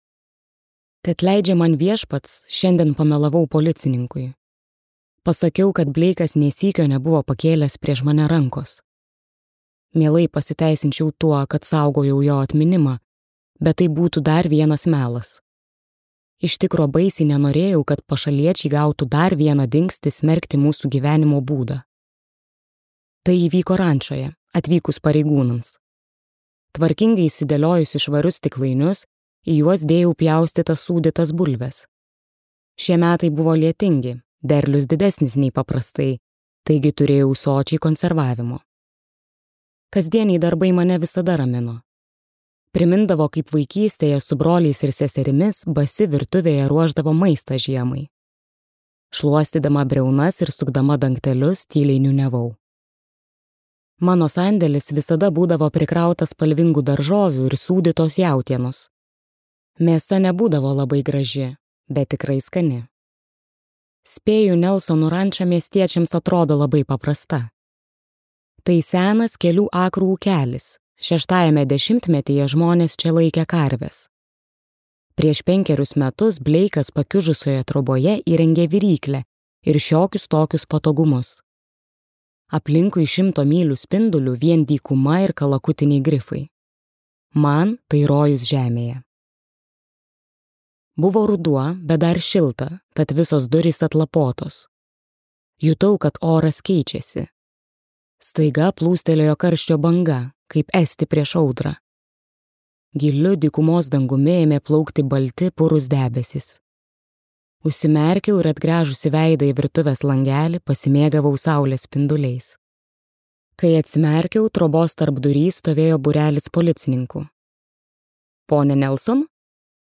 Juodosios našlės | Audioknygos | baltos lankos